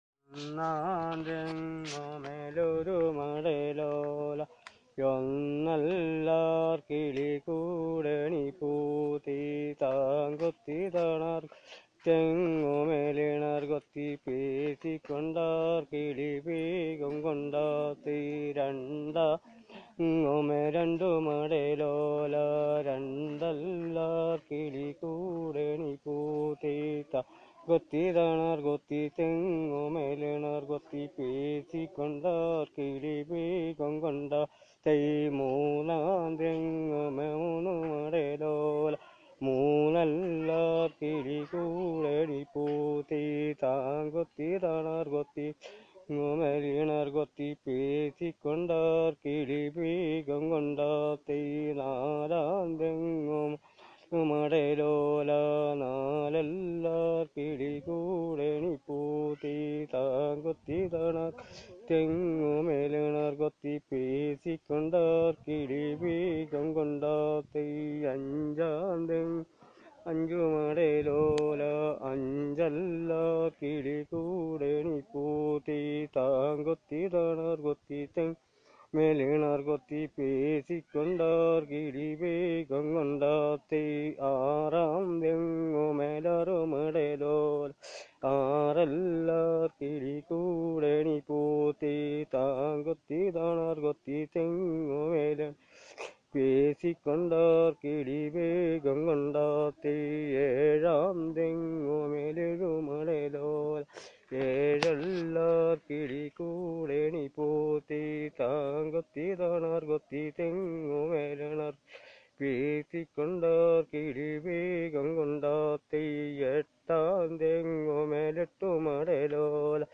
Performance of folk song